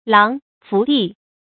琅嬛福地 láng huán fú dì 成语解释 传说中仙人所居多书的洞府。
成语繁体 瑯瑯福地 成语简拼 lhfd 成语注音 ㄌㄤˊ ㄏㄨㄢˊ ㄈㄨˊ ㄉㄧˋ 感情色彩 中性成语 成语用法 作宾语；指洞天福地 成语结构 偏正式成语 产生年代 古代成语 近 义 词 洞天福地 成语例子 清·张岱《快园记》：“如入 琅嬛福地 ，痴龙护门，人迹罕到。”